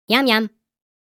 Yum Sound Effect
A funny cartoon voice saying ‘Yam Yam,’ full of playful and cheerful energy. Perfectly delightful and delicious, this sound brings joy and a tasty vibe.
Yum-sound-effect.mp3